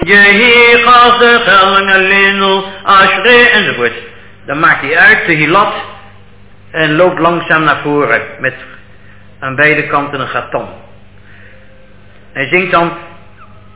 Chazzan